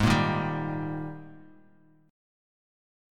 AbM7sus2 chord